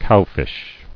[cow·fish]